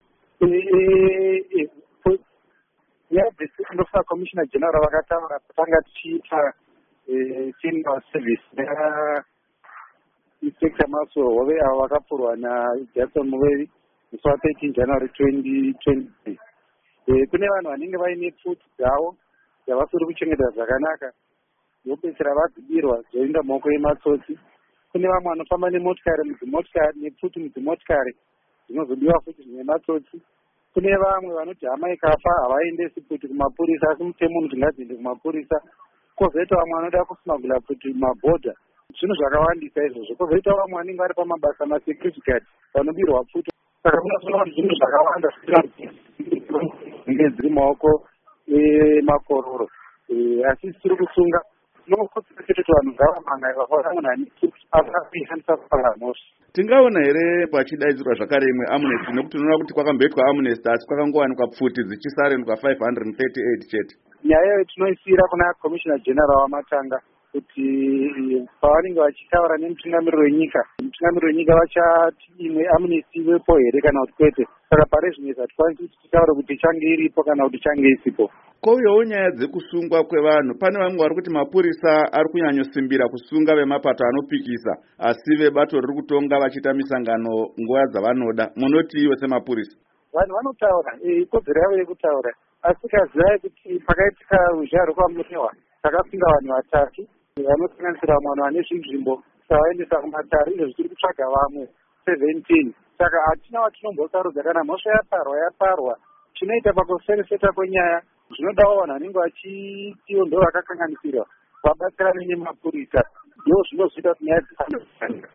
Hurukuro naAssistant Commissioner Paul Nyathi